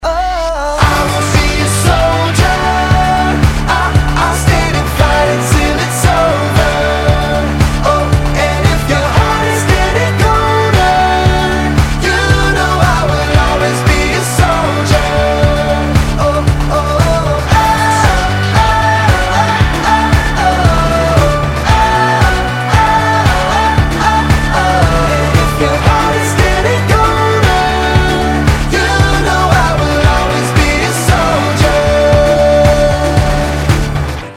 Танцевальные
Метки: поп, веселые, заводные, мужской вокал, классные,